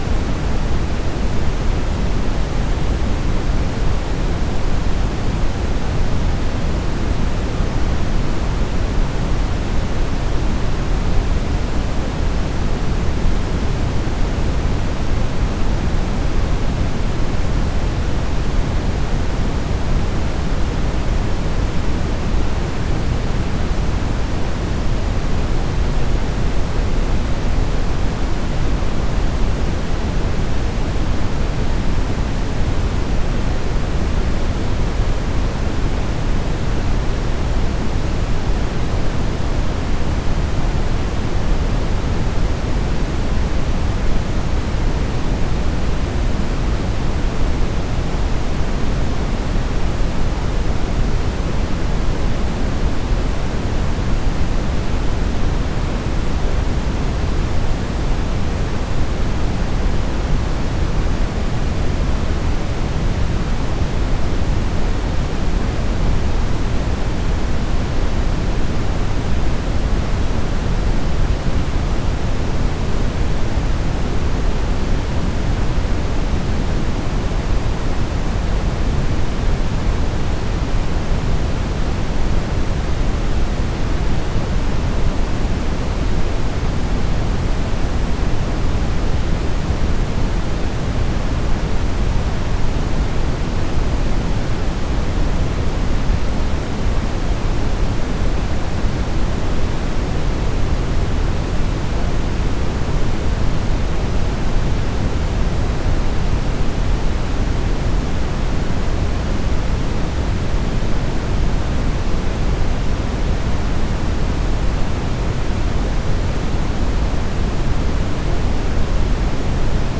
30min of brown noise.opus